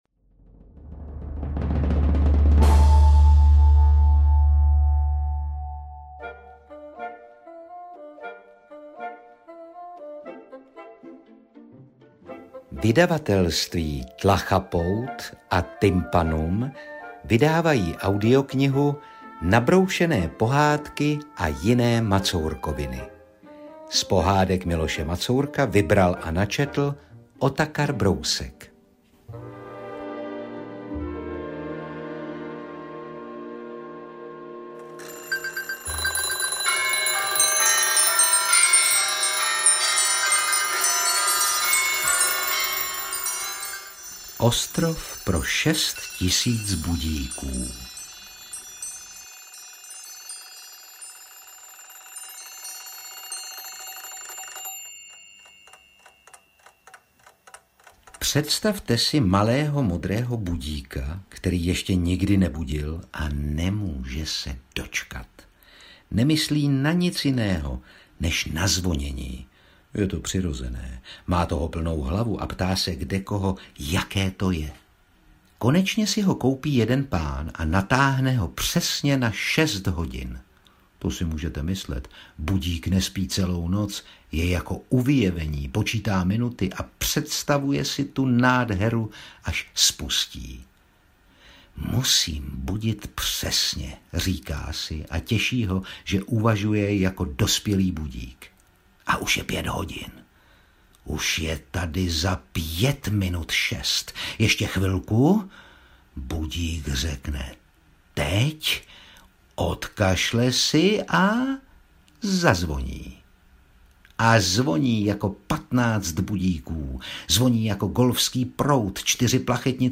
Interpret:  Otakar Brousek
AudioKniha ke stažení, 22 x mp3, délka 2 hod. 15 min., velikost 122,8 MB, česky